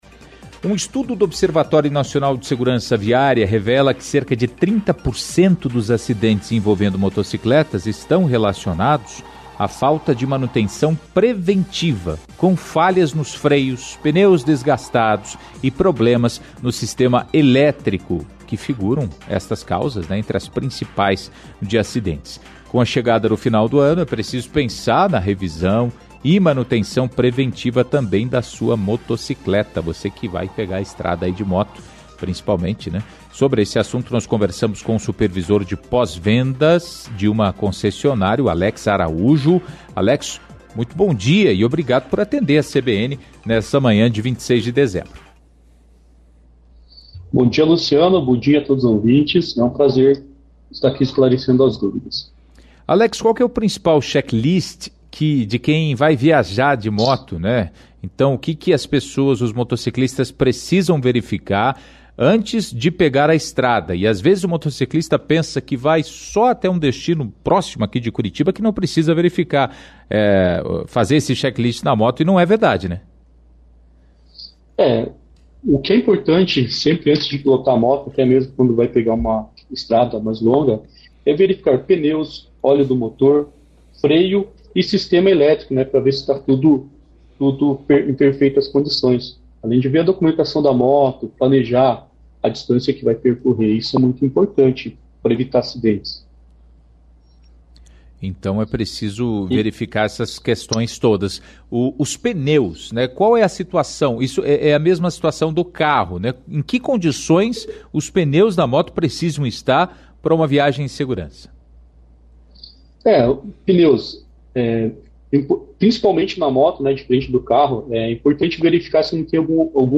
Ouça a entrevista com o supervisor de pós-vendas de uma concessionária de motocicletas